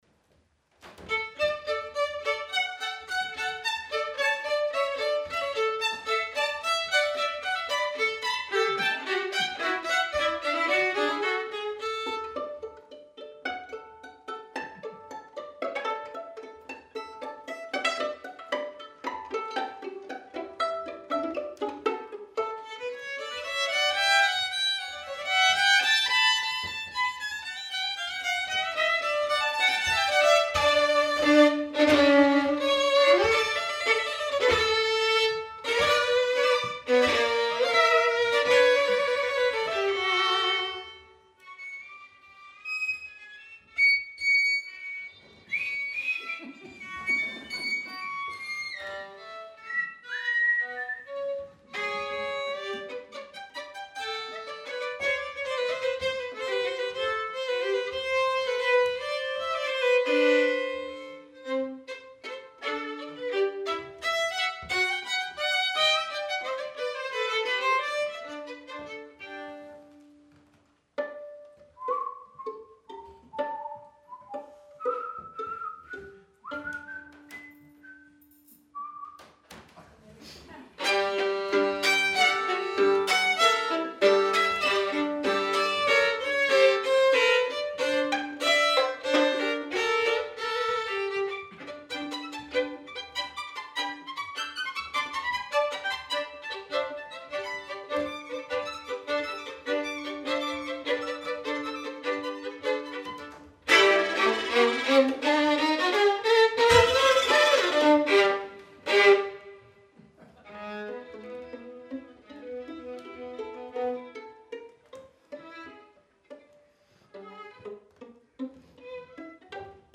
Venue: Bantry Library
Instrumentation Category:Duo
Instrumentation Other: 2vn